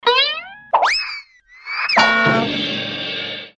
• Качество: 320, Stereo
веселые
без слов
смешные
Мультяшные звуки цирковых прыжков